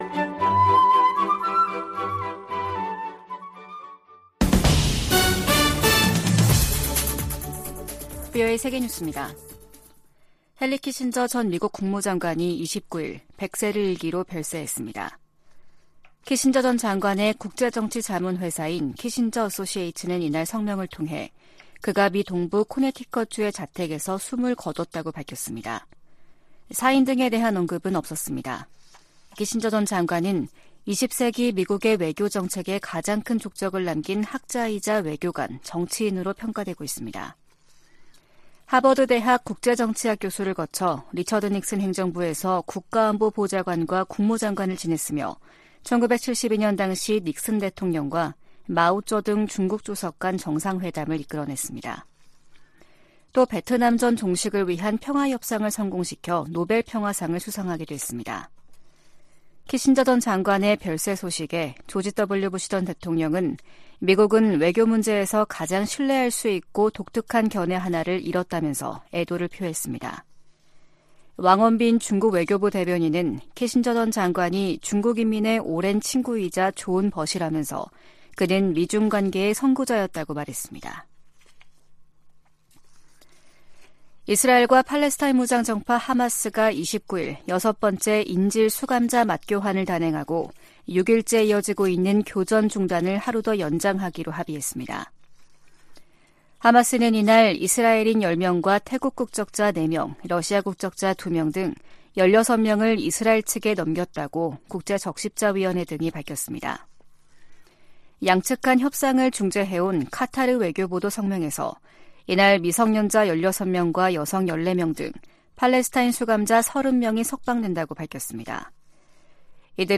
VOA 한국어 아침 뉴스 프로그램 '워싱턴 뉴스 광장' 2023년 12월 1일 방송입니다. 미국 재무부 해외자산통제실이 북한의 해킹 자금을 세탁한 가상화폐 믹서 업체 '신바드'를 제재했습니다. 북한이 제도권 금융기관에서 암호화폐 인프라로 공격 대상을 변경했다고 백악관 고위 관리가 밝혔습니다. 북한-러시아 군사 협력에 제재를 부과하도록 하는 법안이 미 하원 외교위원회를 만장일치 통과했습니다.